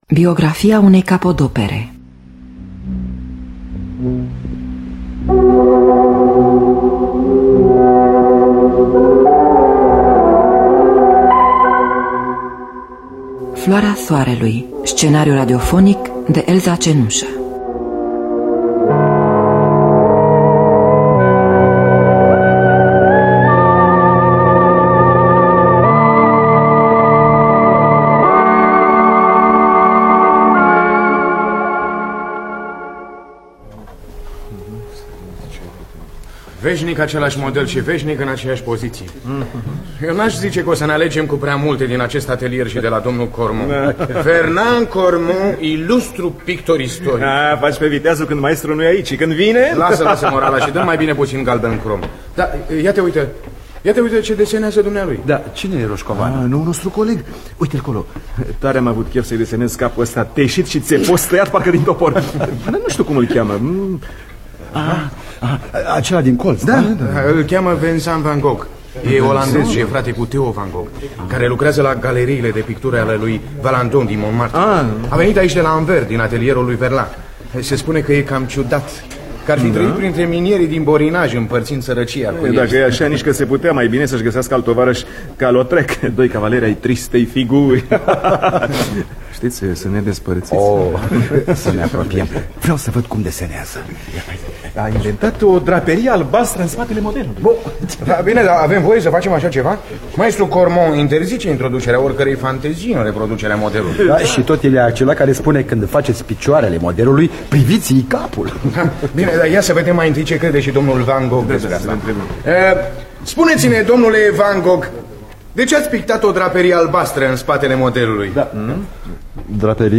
Biografii, Memorii: Vincent van Gogh – Floarea Soarelui (1976) – Teatru Radiofonic Online